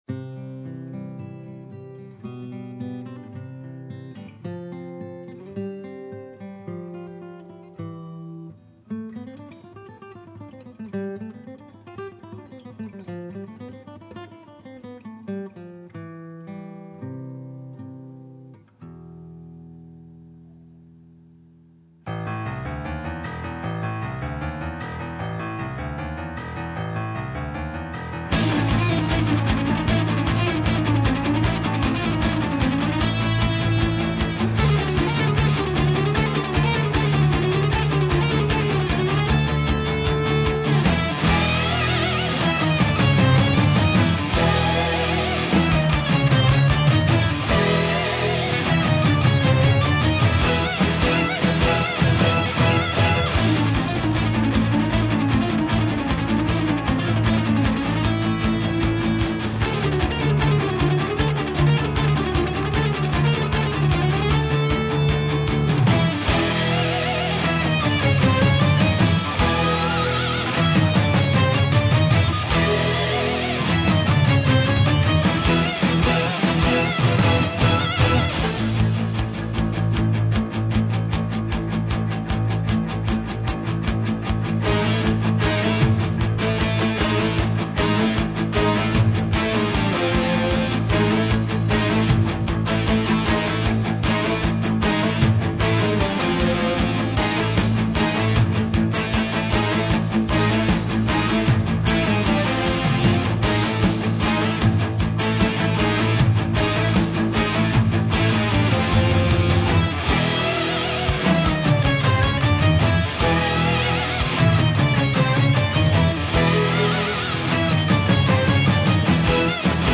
още един прекрасен почти-инструментал -